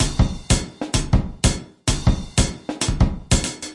奇数时间节拍120bpm " 奇数时间节拍120bpm14
描述：奇数时间节拍120bpm
Tag: 回路 常规 时间 节奏 120BPM 敲击环 量化 鼓环 有节奏